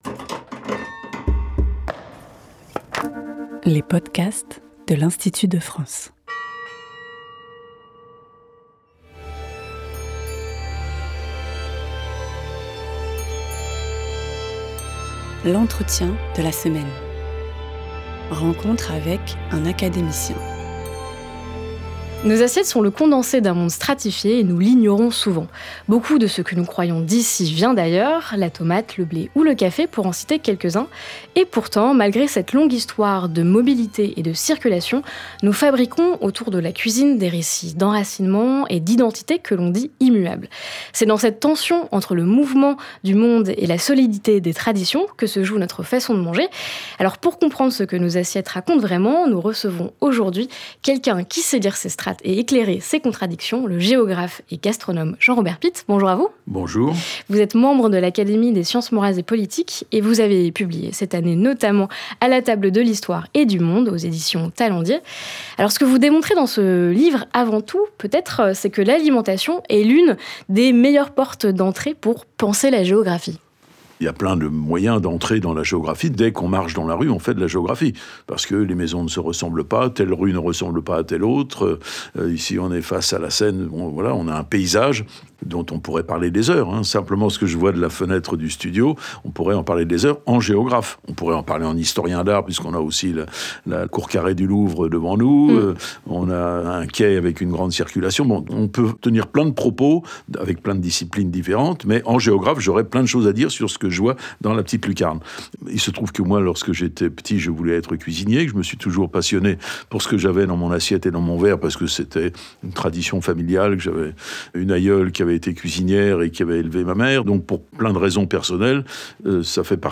Dans cet entretien, Jean-Robert Pitte montre en quoi l’alimentation constitue une porte d’entrée privilégiée pour penser la géographie et l’histoire culturelle. À partir d’exemples concrets comme le petit-déjeuner, le cassoulet ou les frites, il rappelle que la plupart des plats dits « traditionnels » sont le fruit de circulations anciennes, bien antérieures à la mondialisation contemporaine.